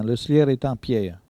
Fonds Arexcpo en Vendée
Catégorie Locution